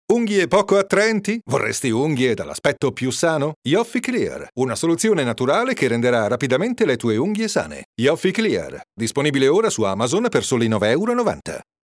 Italian voice overs